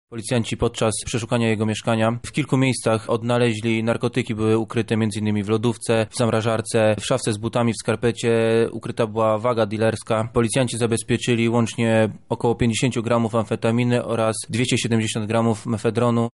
O szczegółach mówi